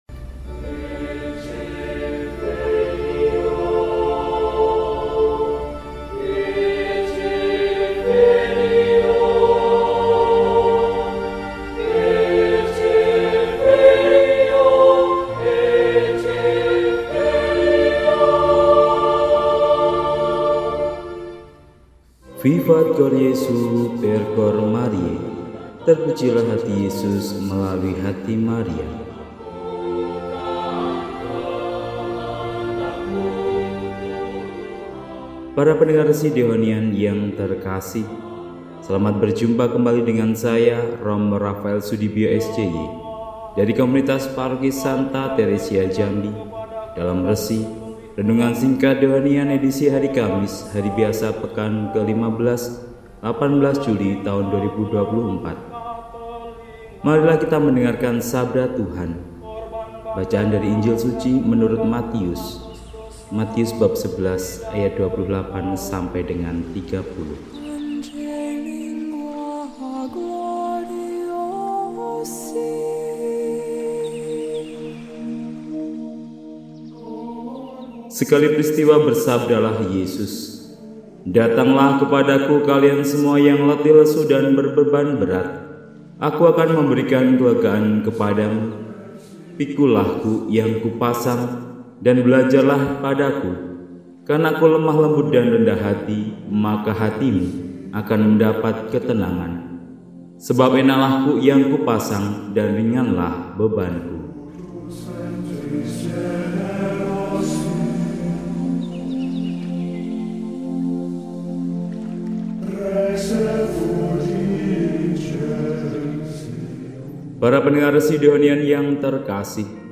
Kamis, 18 Juli 2024 – Hari Biasa Pekan XV – RESI (Renungan Singkat) DEHONIAN